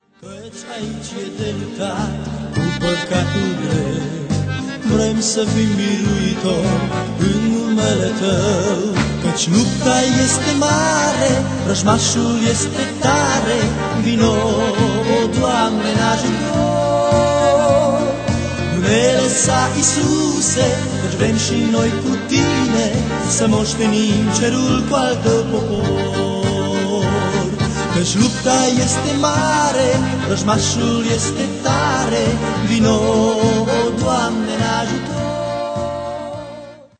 Acordeonist convins, dar fara sa abuzeze de acest instrument